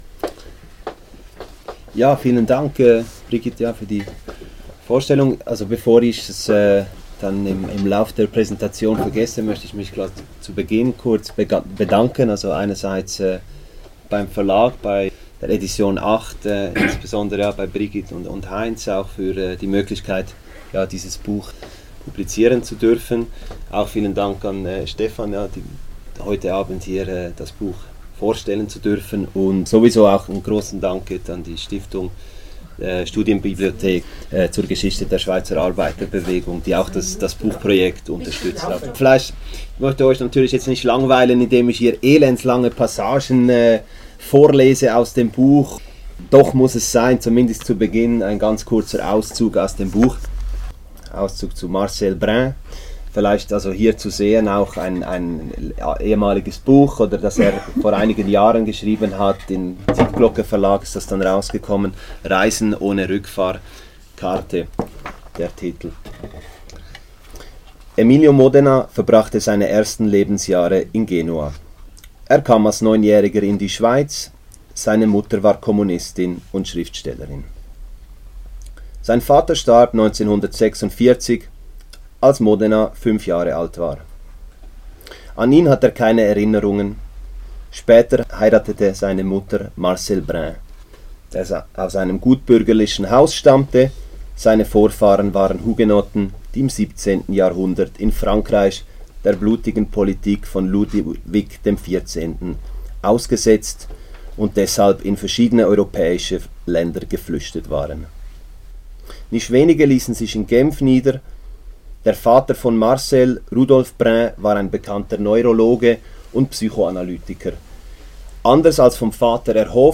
Buchvorstellung